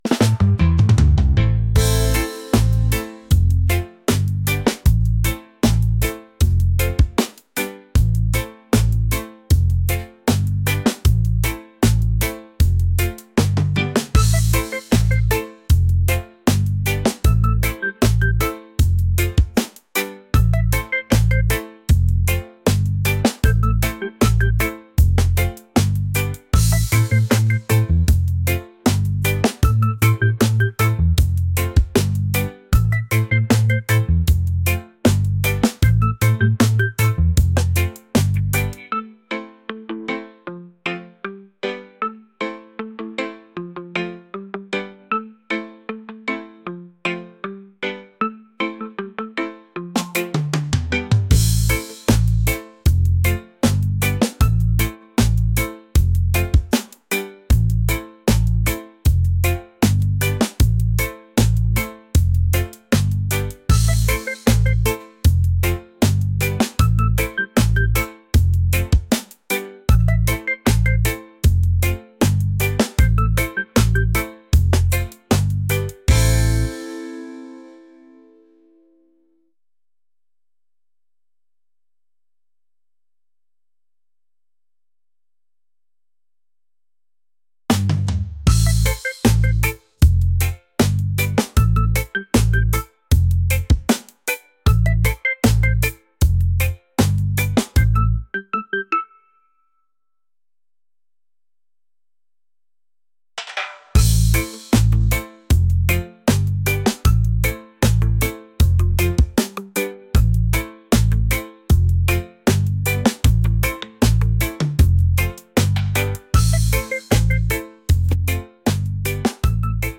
laid-back | reggae | positive | vibes